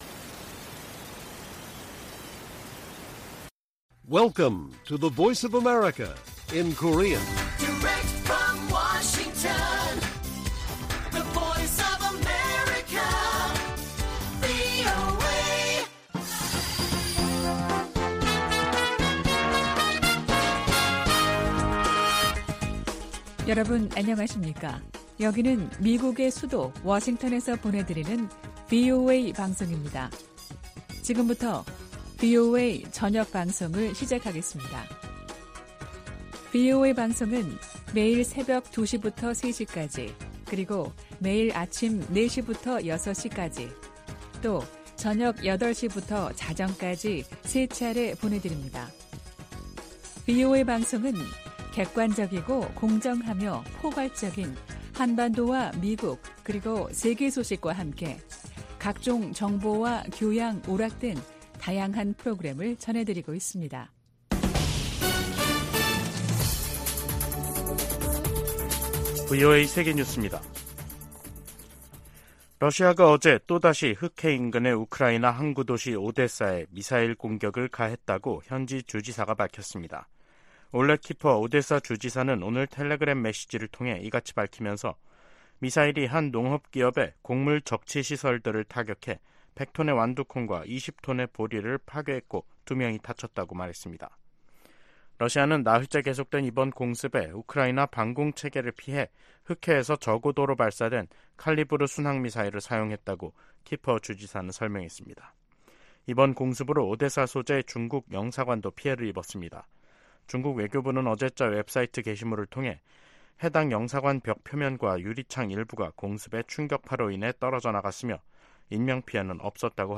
VOA 한국어 간판 뉴스 프로그램 '뉴스 투데이', 2023년 7월 21일 1부 방송입니다. 백악관은 월북 미군 병사의 안전과 소재 파악에 최선을 다하고 있지만 현재 발표할 만한 정보는 없다고 밝혔습니다. 미국과 한국의 핵협의그룹(NCG)을 외교・국방 장관 참여 회의체로 격상하는 방안이 미 상원에서 추진되고 있습니다. 미 국방부가 전략핵잠수함(SSBN)의 한국 기항을 비난하며 핵무기 사용 가능성을 언급한 북한의 위협을 일축했습니다.